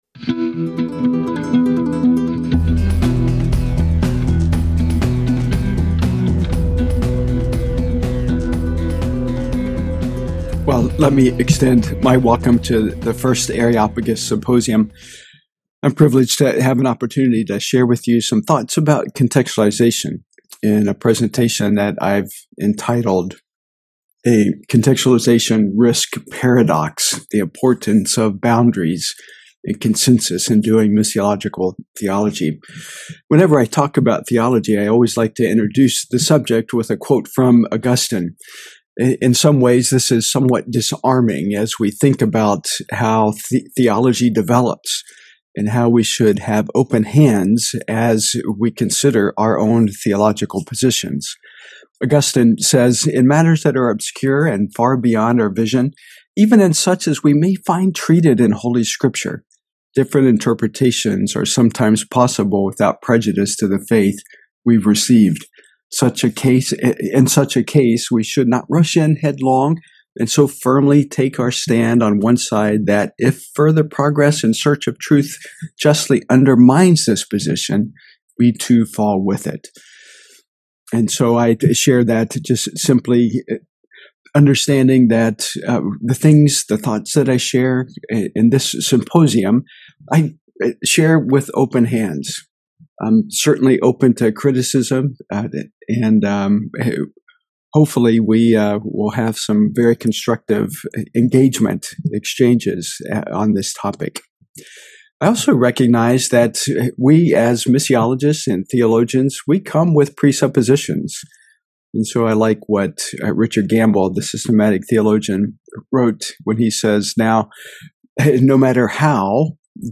On this special episode of the Ephesiology Podcast, we listen in to the first Areopagus Symposium